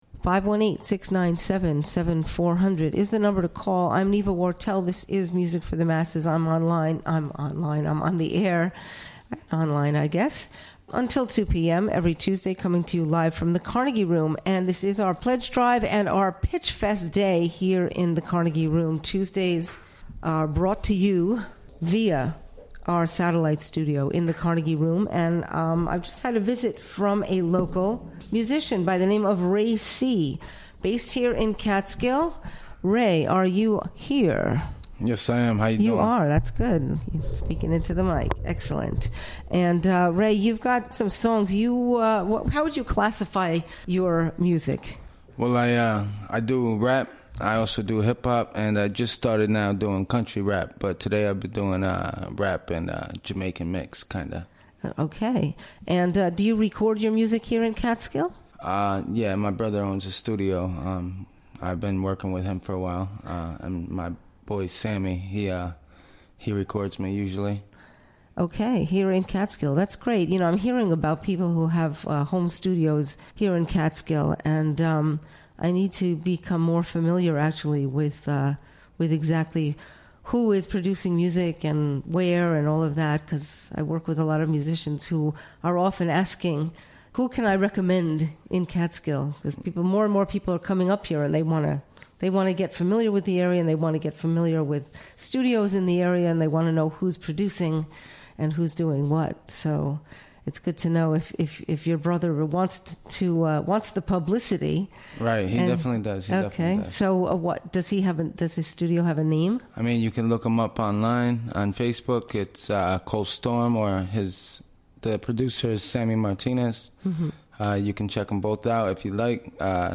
2pm WGXC's Harvest Fall 2017 Pledge Drive is here, and...